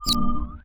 UIClick_Soft Dreamy Whistle Wobble 03.wav